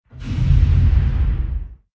Descarga de Sonidos mp3 Gratis: explosion.
descargar sonido mp3 explosion